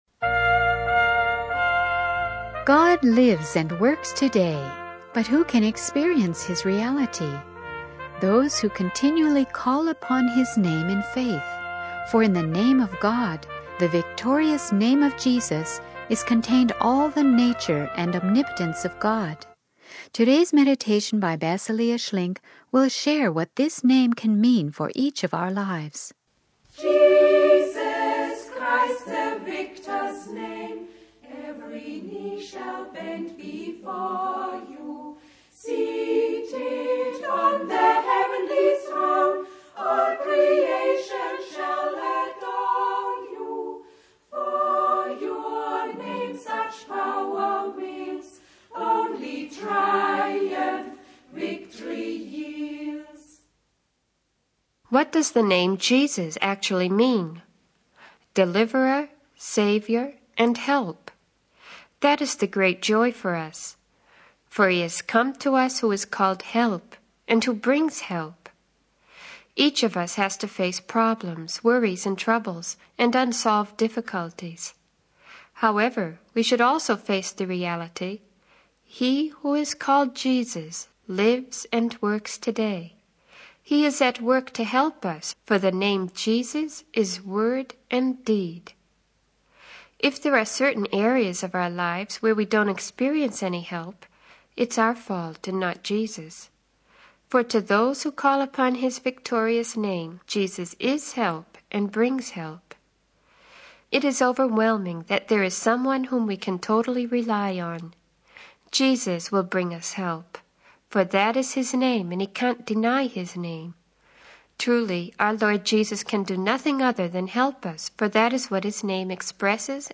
In this sermon, the speaker emphasizes the power and significance of the name of Jesus.